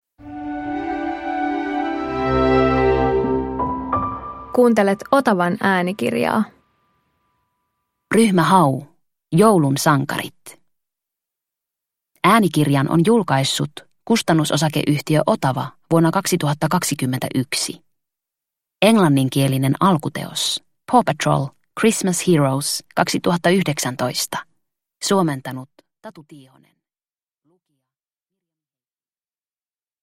Ryhmä Hau - joulun sankarit – Ljudbok – Laddas ner